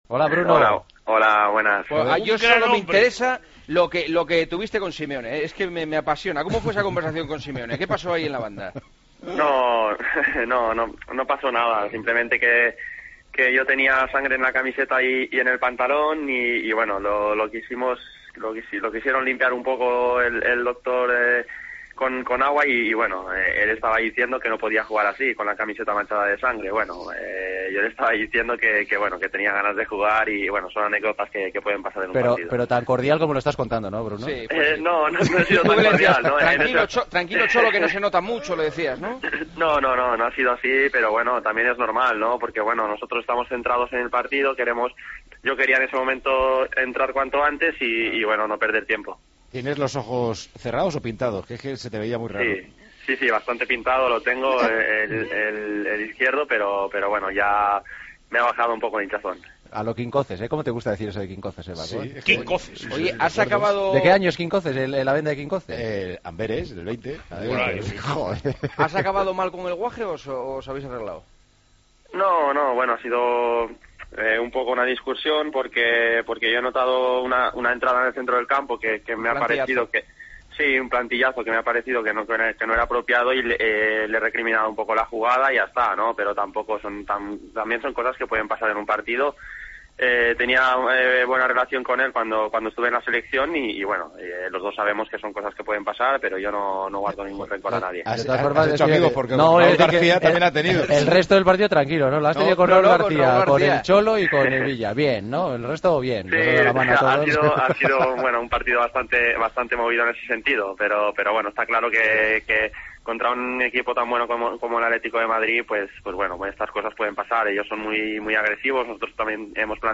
Charlamos con el que, para Marcelino, es uno de los mejores jugadores de la Liga.
Con Paco González, Manolo Lama y Juanma Castaño